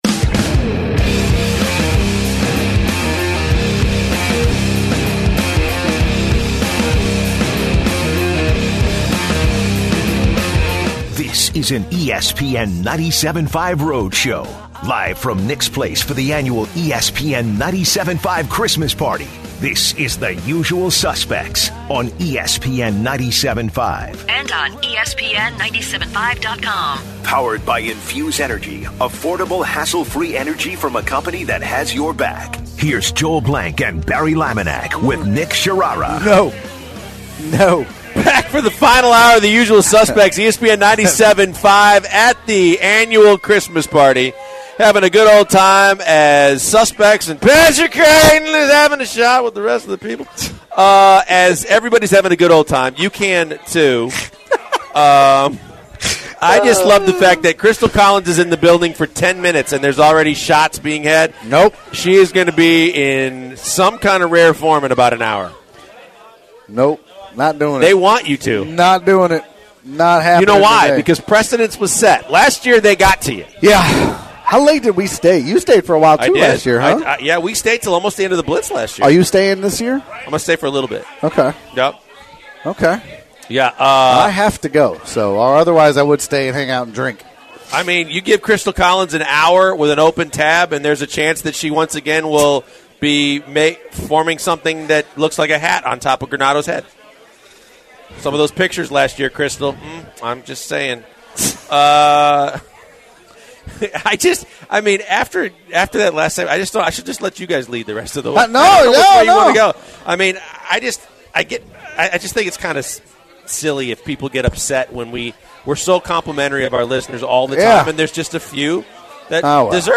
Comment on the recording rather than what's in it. broadcast live from Nick's Place for our ESPN 97.5 Christmas Party and there is plenty of fun and frivolity.